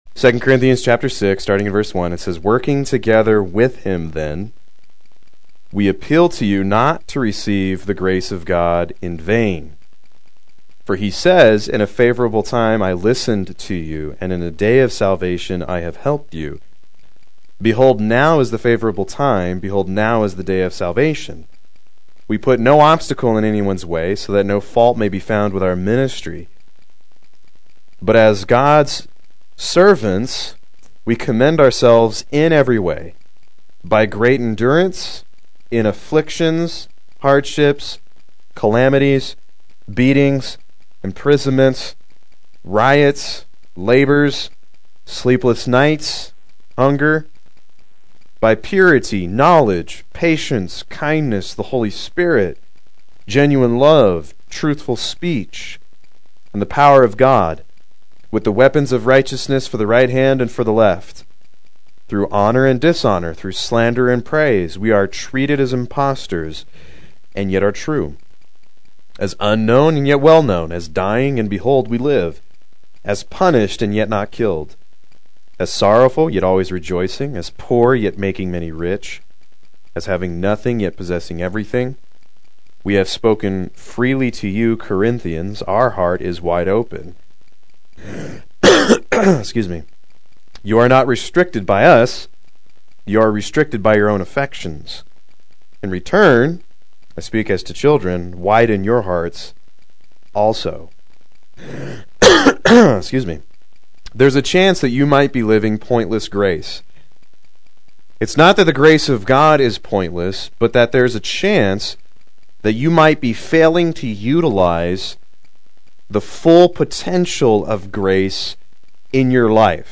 Proclaim Youth Ministry - 04/21/17
Play Sermon Get HCF Teaching Automatically.